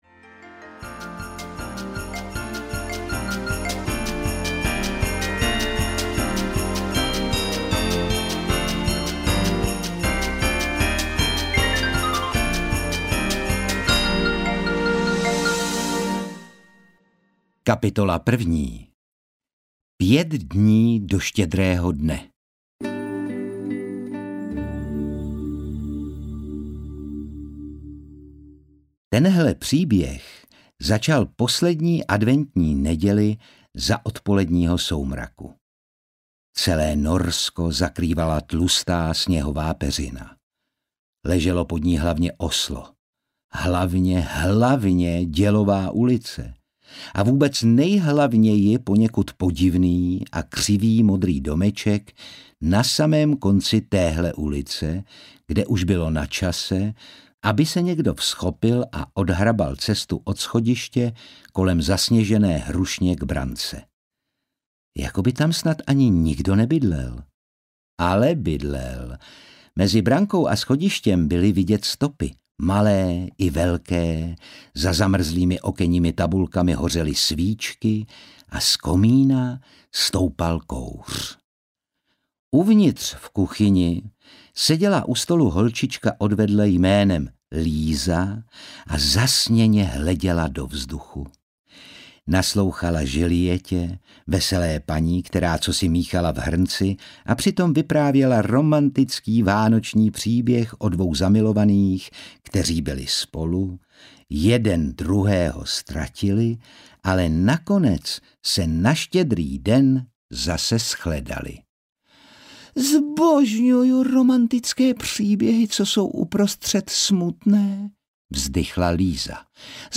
Zachrání doktor Proktor Vánoce? audiokniha
Ukázka z knihy
• InterpretOtakar Brousek ml.